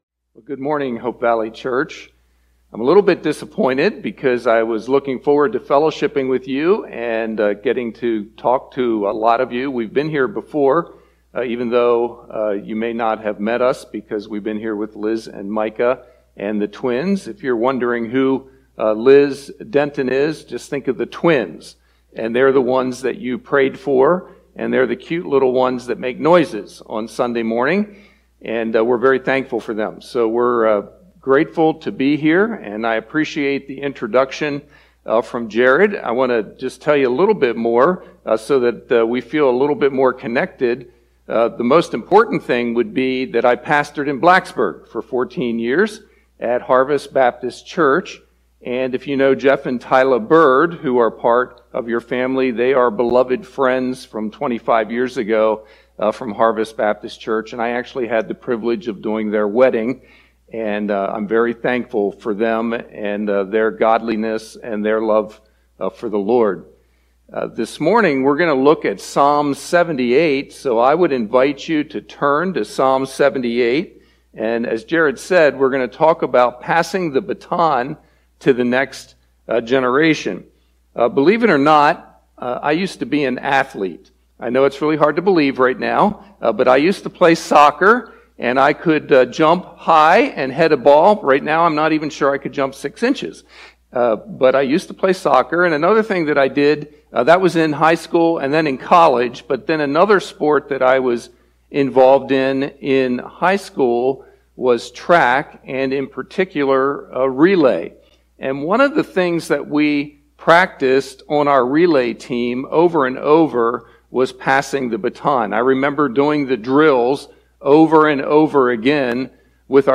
sermon-audio-trimmed-1.mp3